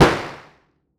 Start Gun.wav